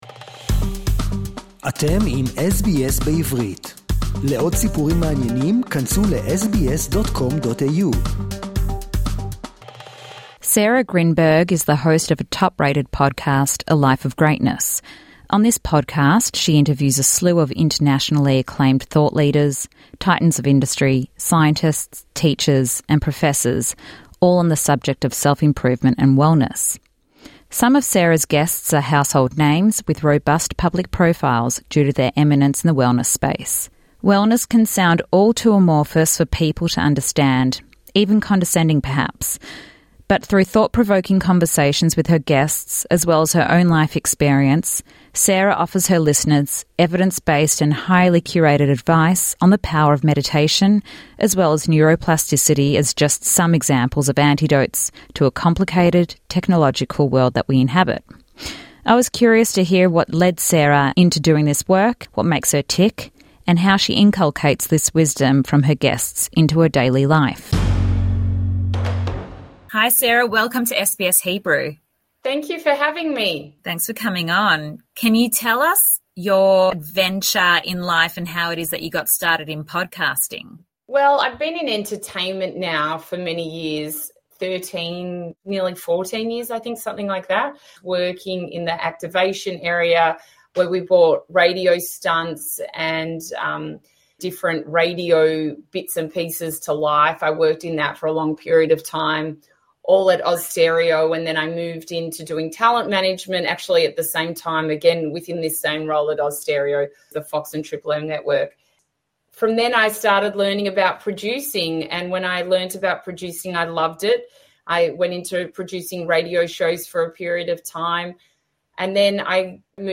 A Conversation with Wellness Podcaster